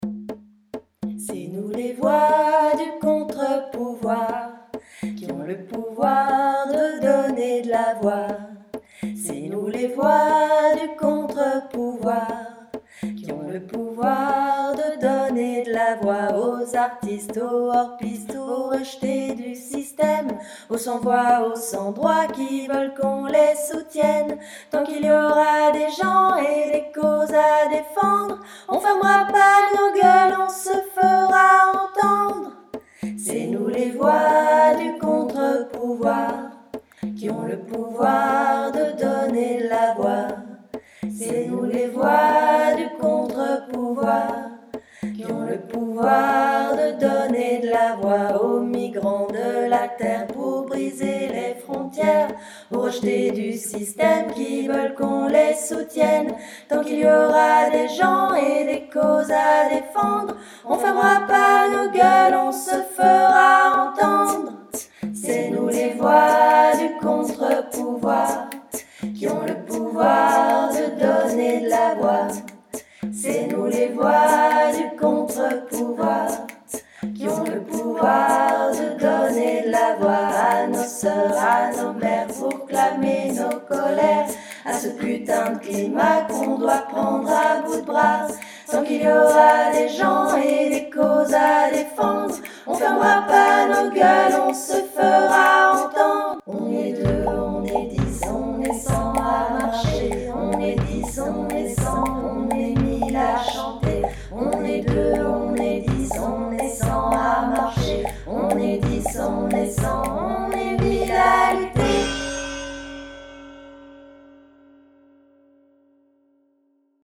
Donner de la voix Tutti